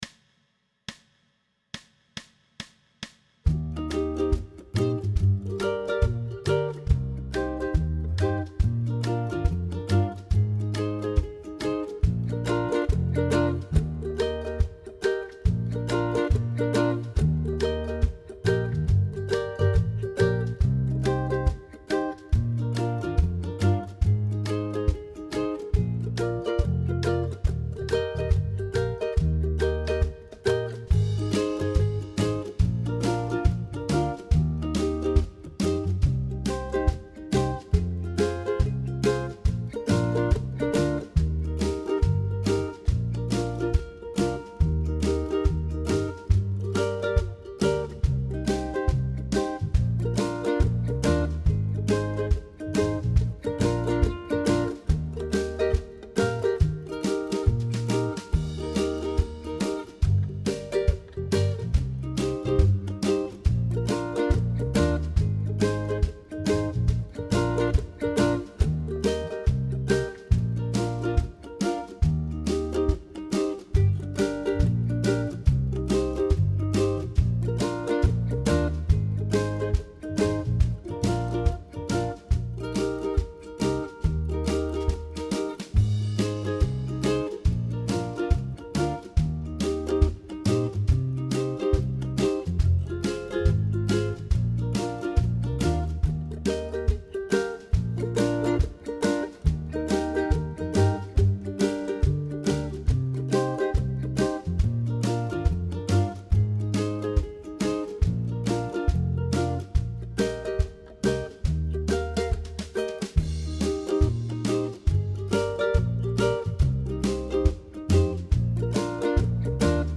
Pop style jam track with Ukulele Rhythm
Tempo: 140BPM
Key of Eb
ukulele-pop-140-eb.mp3